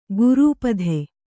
♪ gurūpadhe;